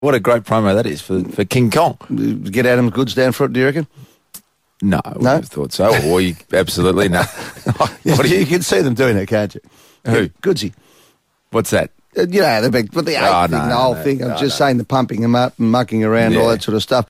Eddie McGuire's on-air gaffe